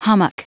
Prononciation du mot : hummock
hummock.wav